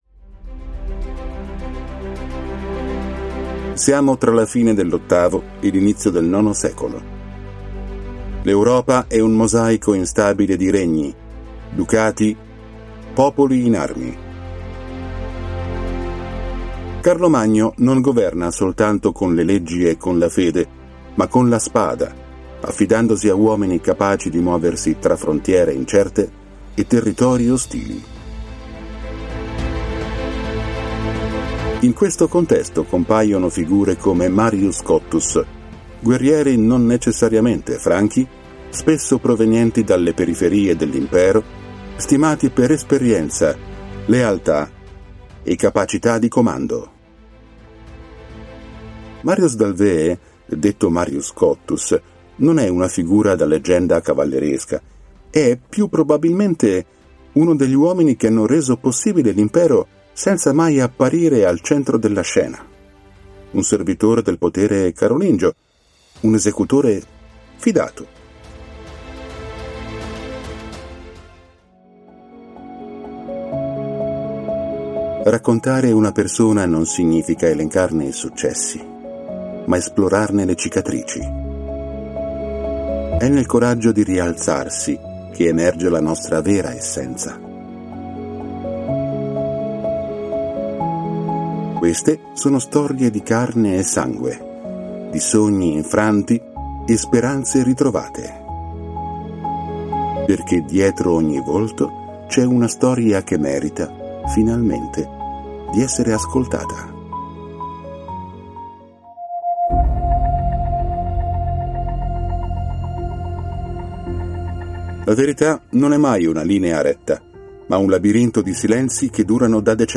La mia voce è baritonale, calda e musicale: matura, chiara e naturale, con un tono accogliente ma deciso, ideale per progetti che richiedono credibilità e presenza.
Sprechprobe: Sonstiges (Muttersprache):